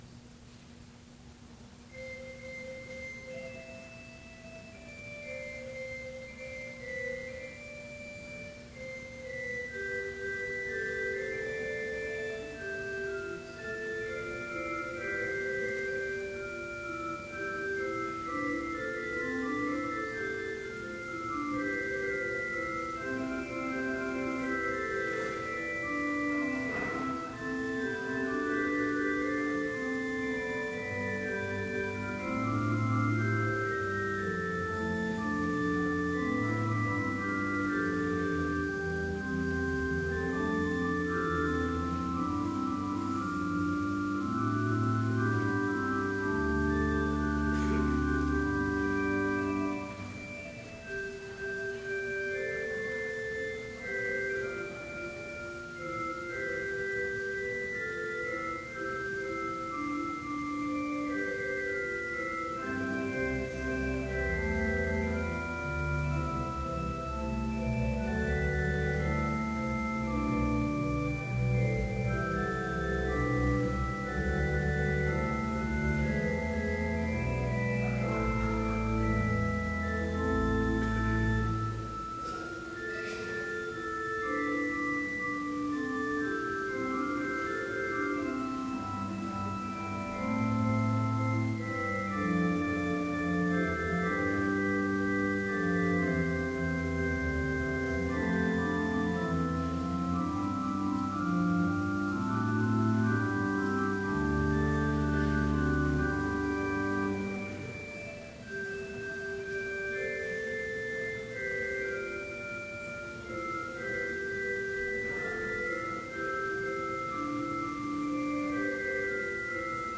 Complete service audio for Summer Chapel - August 8, 2012